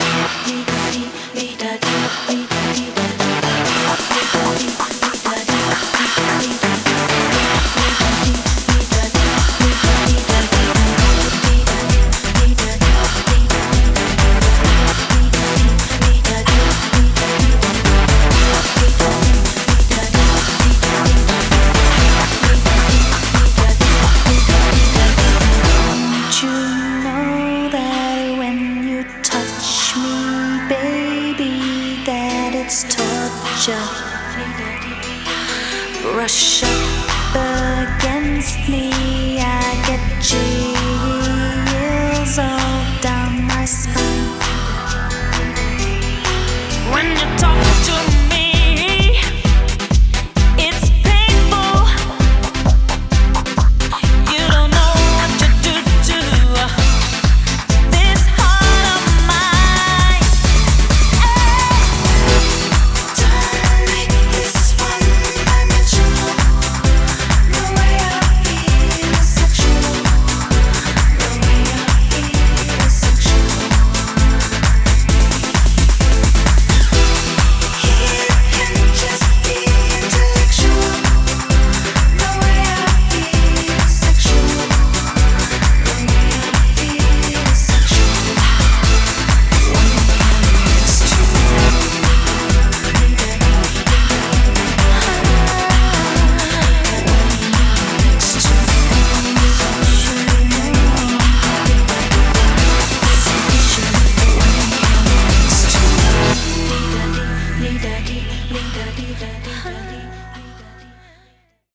BPM66-132
Audio QualityPerfect (High Quality)
Genre: Tribal House